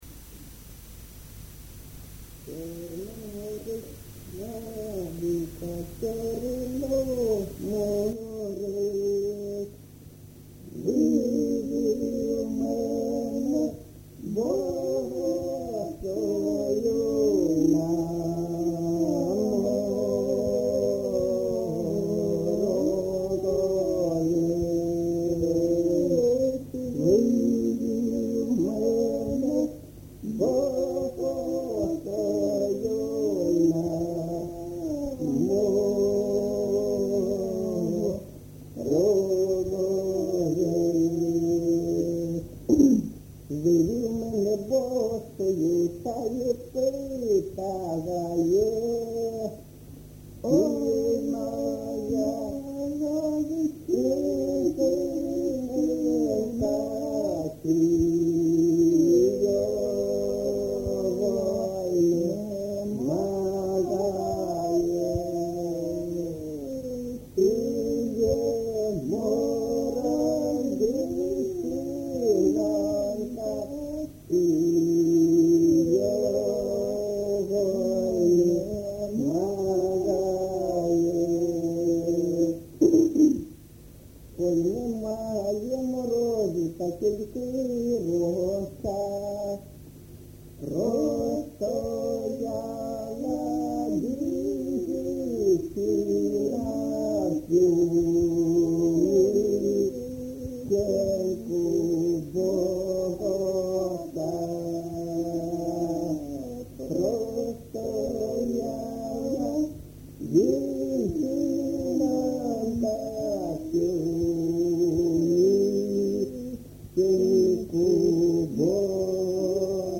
ЖанрПісні з особистого та родинного життя
Місце записум. Часів Яр, Артемівський (Бахмутський) район, Донецька обл., Україна, Слобожанщина